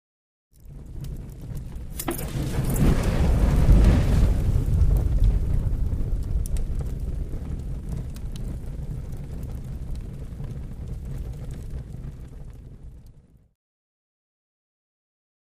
LighterFluidPoured HI027001
Lighter Fluid Poured on Fire